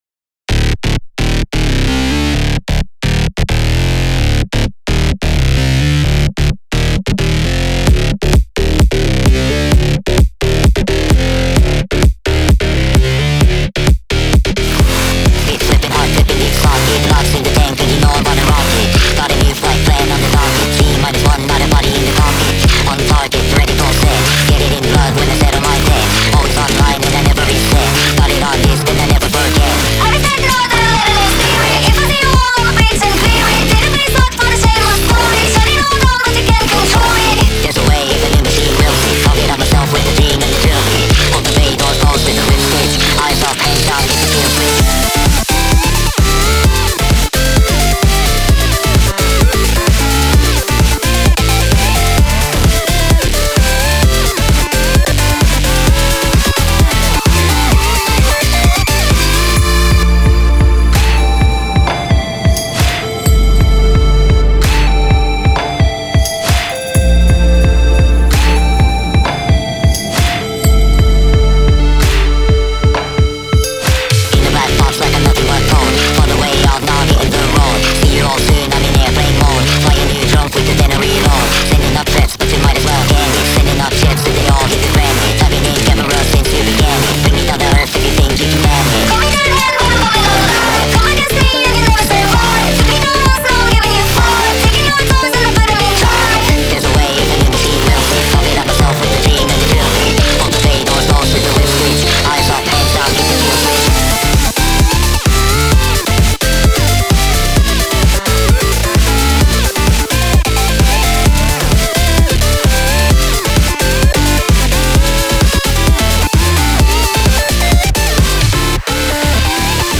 BPM130-130
Audio QualityPerfect (High Quality)
Full Length Song (not arcade length cut)